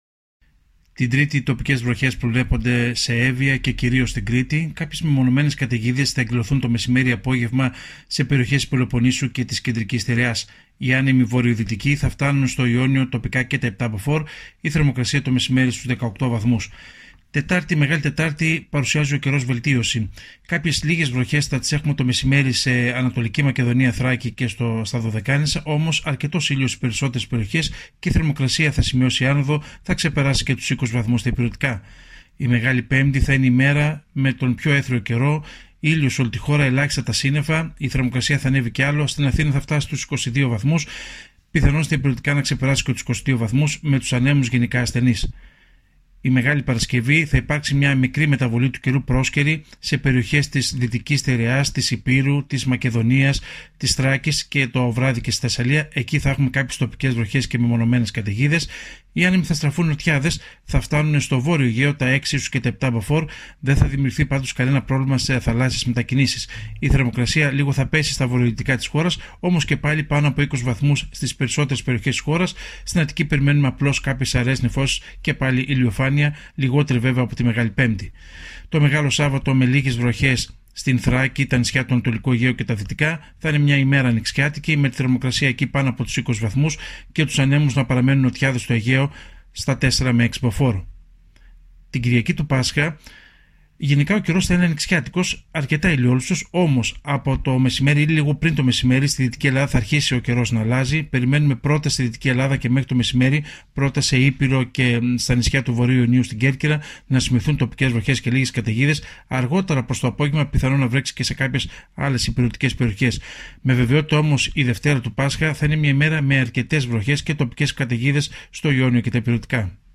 Ακούστε την πρόγνωση του καιρού για το Πάσχα από τον μετεωρολόγο